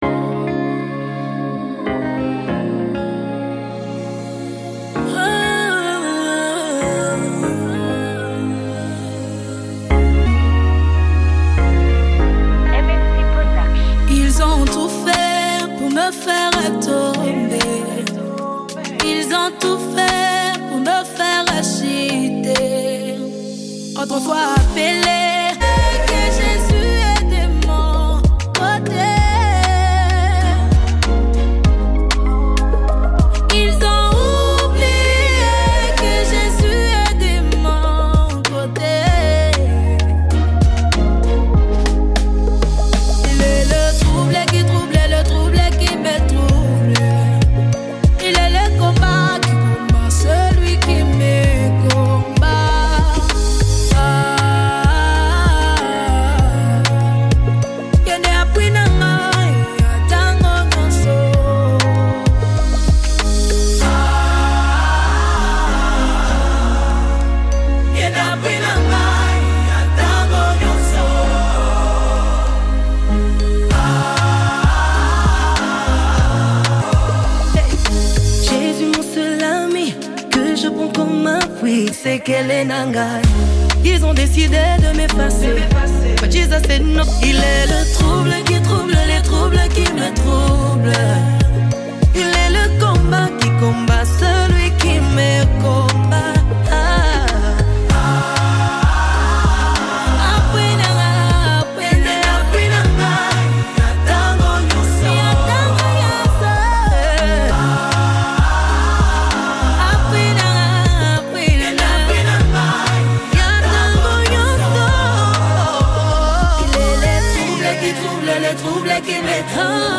gospel
anchored by a powerful melody and spirit-filled lyrics.